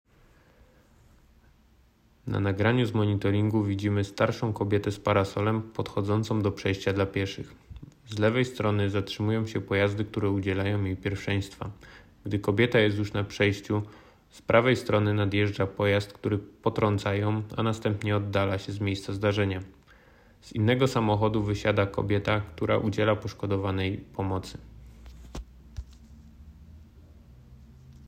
Nagranie audio potracenie_oswiecimska_audiodeskrypcja.m4a
Opis nagrania: audiodeskrypcja do nagrania z monitoringu